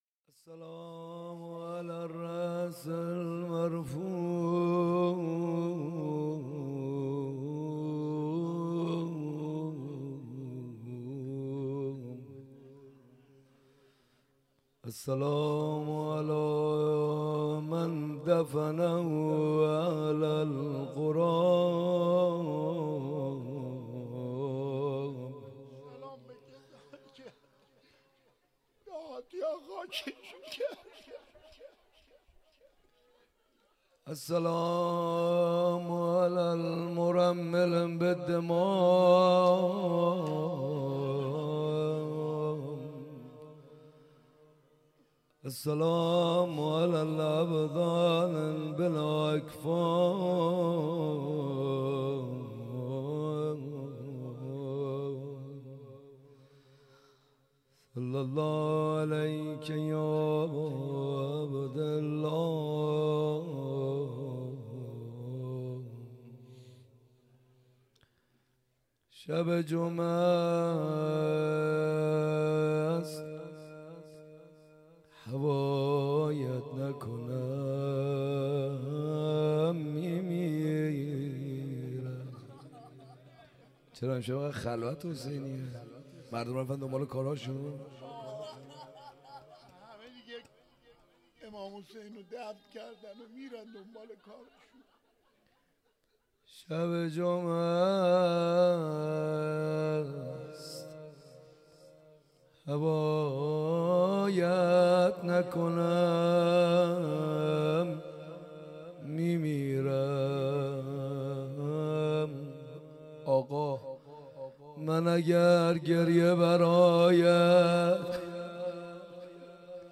حسینیه کربلا
روضه ورود به کوفه و شهادت امام سجاد (ع) - شب 16 محرم 1399